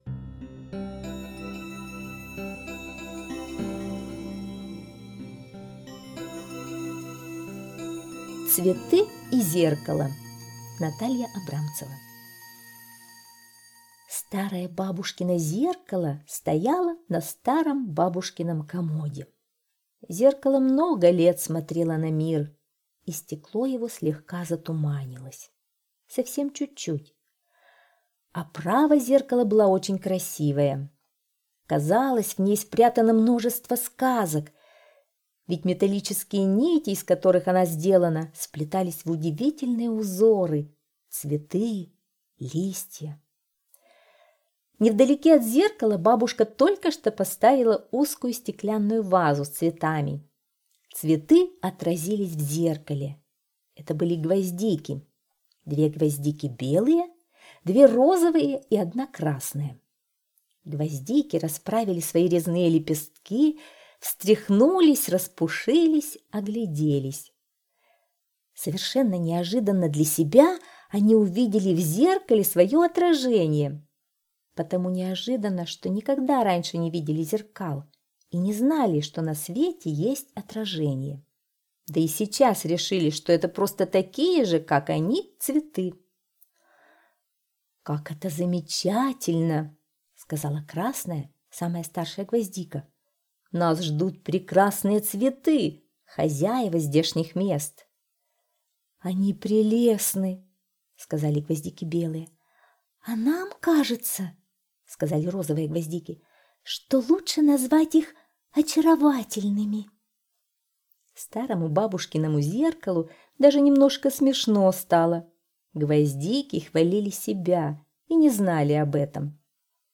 Цветы и зеркало - аудиосказка Абрамцевой Н.К. Однажды бабушка поставила вазу с гвоздиками на комод, где стояло зеркало в красивой раме.